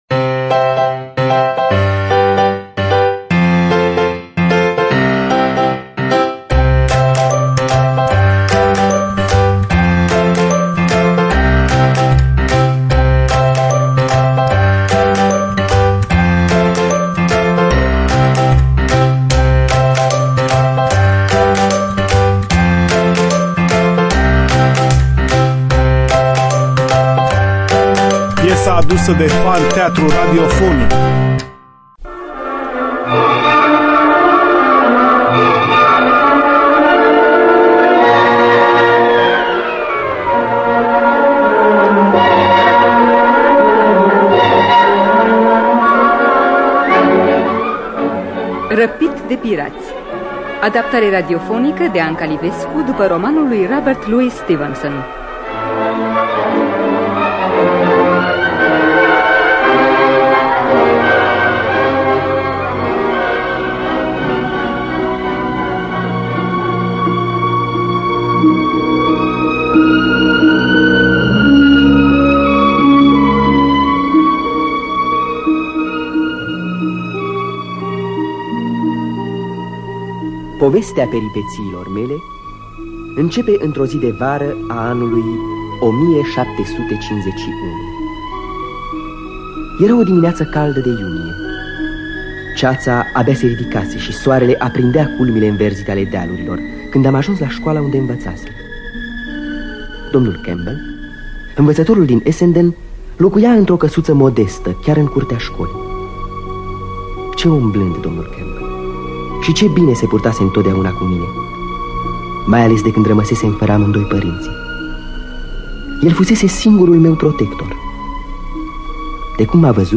Răpit de piraţi de Robert Louis Stevenson – Teatru Radiofonic Online